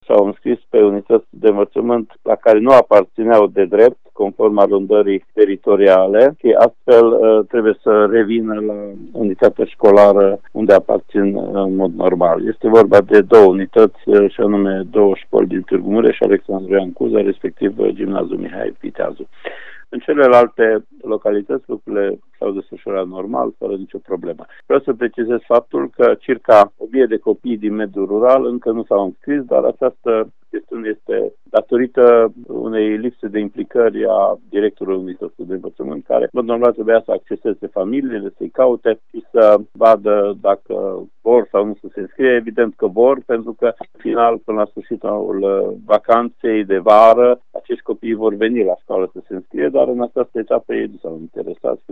Inspectorul școlar general al județului Mureș, Ștefan Someșan, a precizat că 35 de copii nu au fost validați pentru că nu s-au înscris la școala la care trebuia: